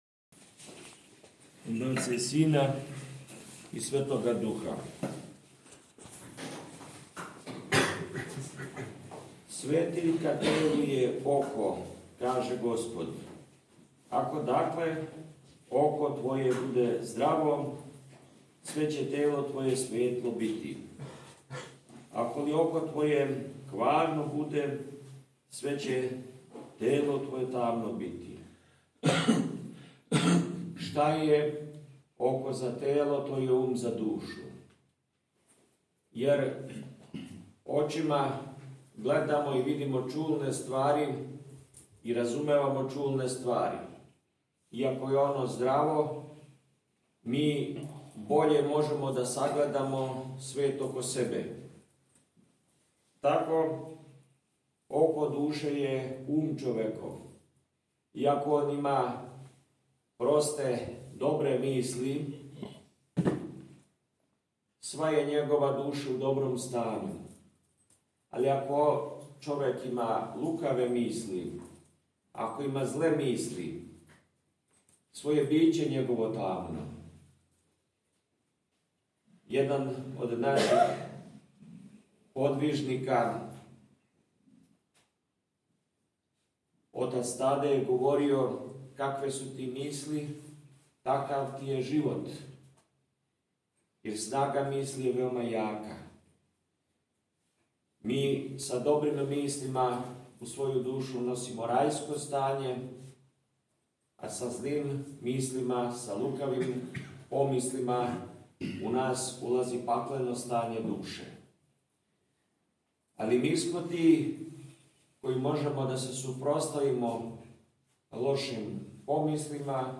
Његово Преосвештенство Епископ липљански г. Доситеј је у недељу трећу по Духовима, 25. јуна 2023. године, свету архијерејску Литургију служио у манастиру Светог Георгија - Ћелије колубарске код Лајковца.